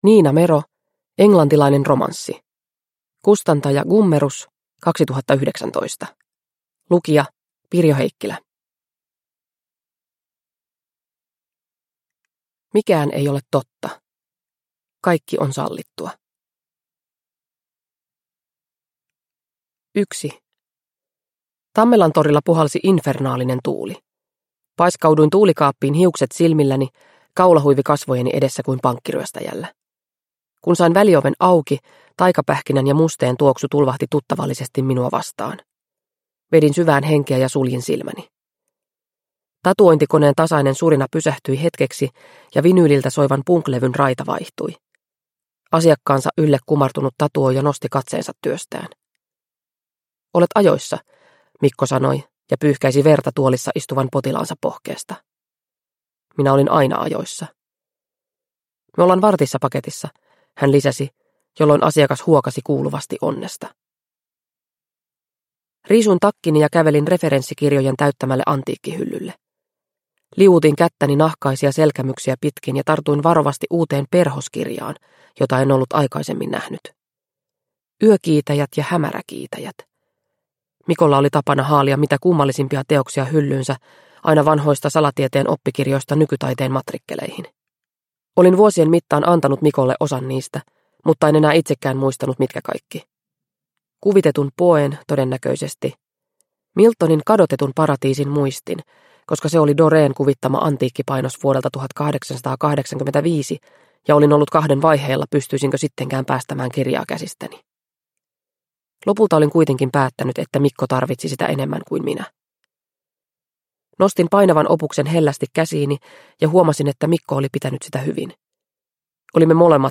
Englantilainen romanssi – Ljudbok – Laddas ner